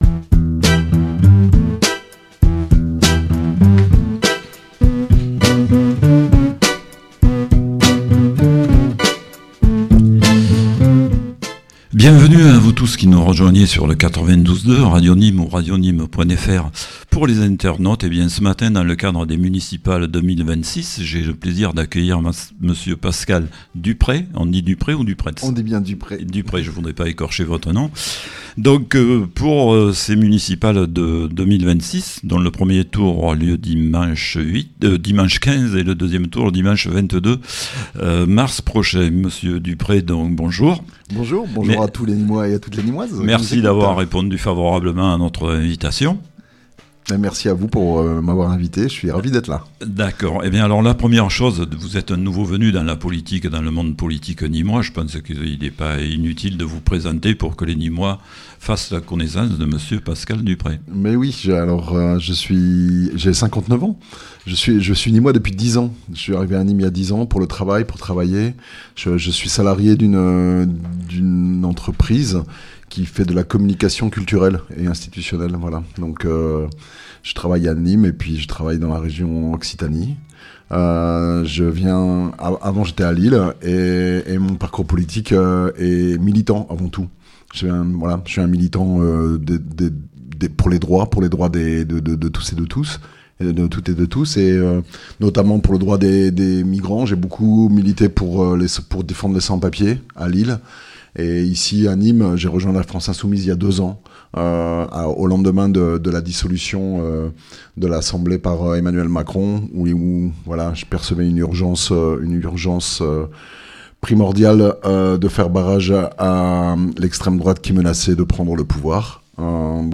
Entretien
Émission spéciale élections municipales 2026